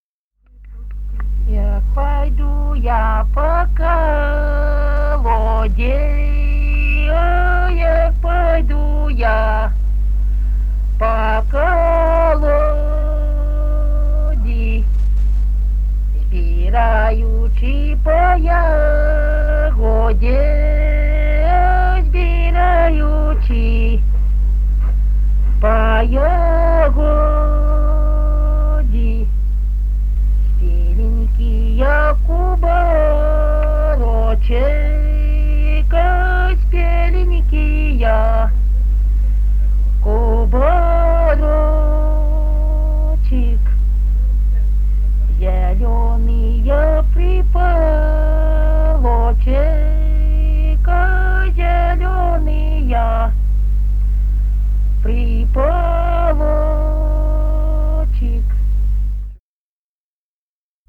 Русские народные песни Красноярского края.
«Як пойду я по колоде» (жнивная). с. Бражное Канского района.